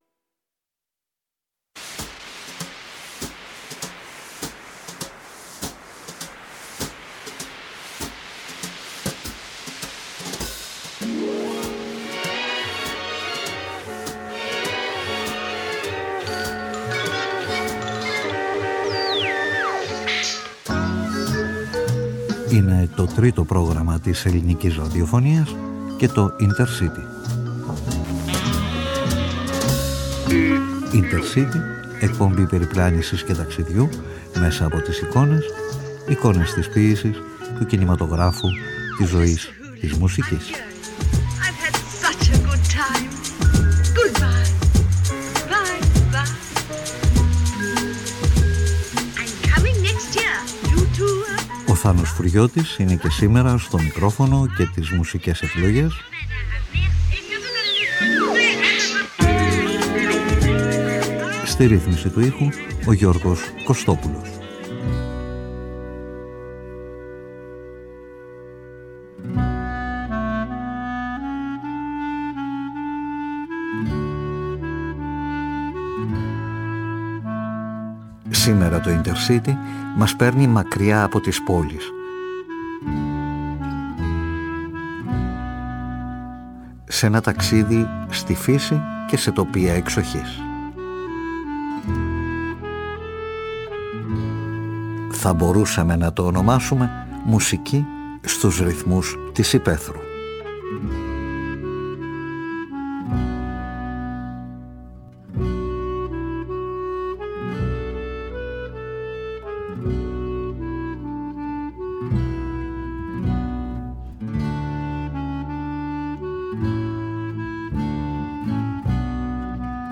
Δώδεκα ορχηστρικά μουσικά έργα, 12 μινιατούρες ταξιδιών, με εμφανείς επιρροές από την παράδοση, συνδυασμένα με την κλασική παιδεία του δημιουργού τους και με σαφείς αναφορές στην φύση και στις εποχές της.